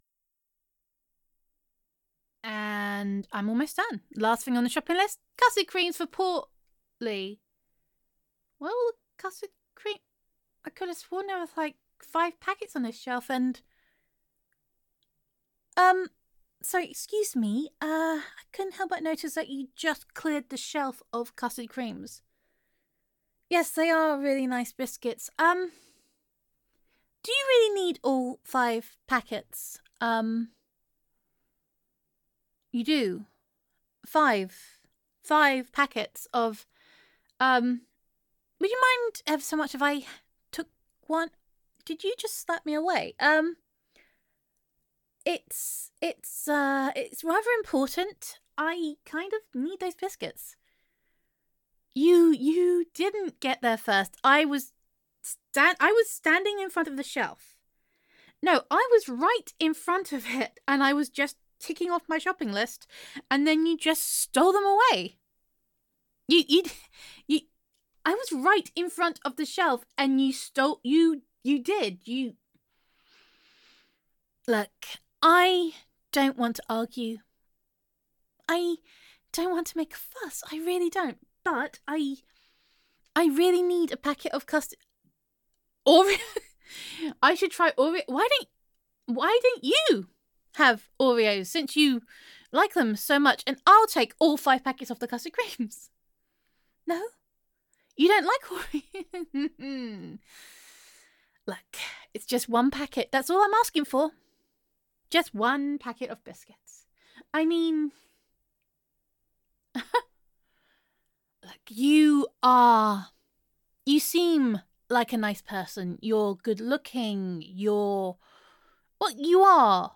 [F4A] Shopping with Portly and Sergei [Scarper!][Give Me the Damn Custard Creams][You Are Not Bad Looking for a Biscuit Thief][We Are Running out of Supermarkets][Utterly Ridiculous][Gender Neutral][A Day in the Life of Honey]